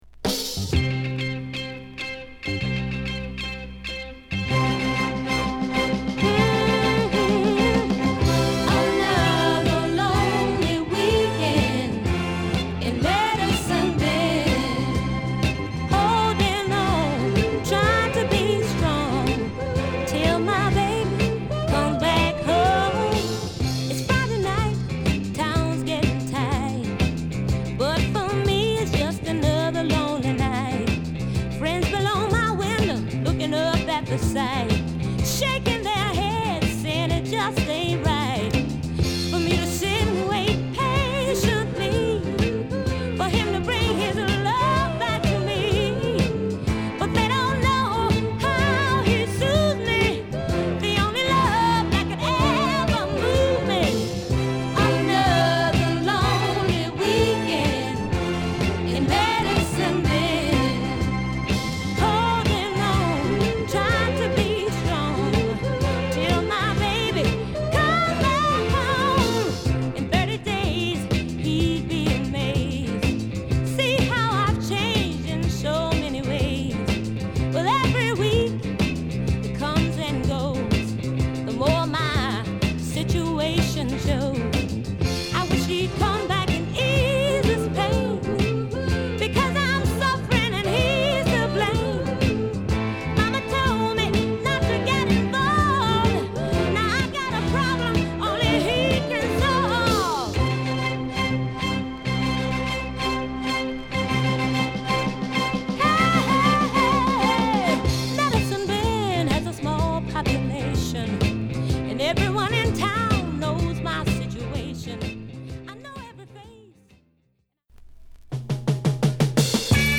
南部の土臭さとは違う爽快で高らかなレディソウルを披露！